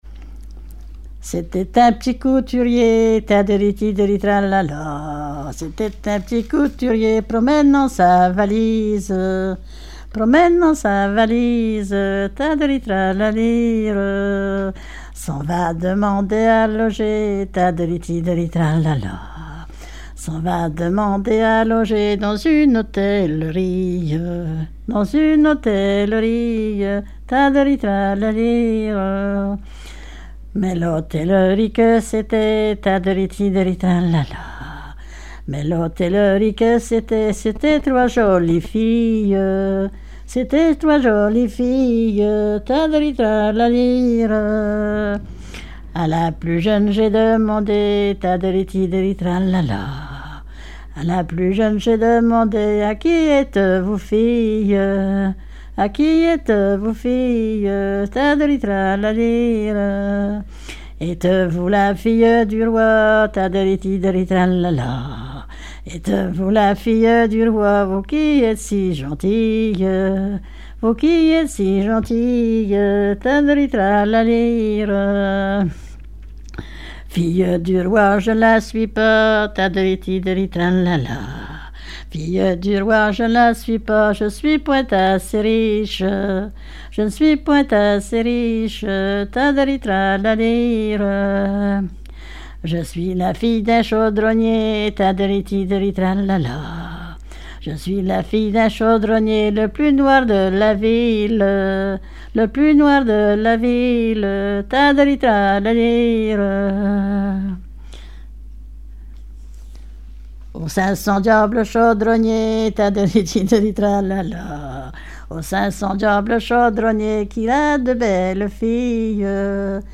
danse : ronde
chansons traditionnelles et populaires
Pièce musicale inédite